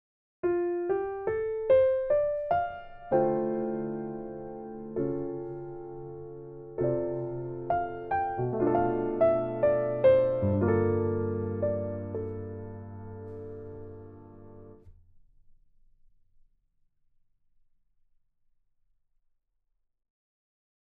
Step 1: Melody with Basic Chords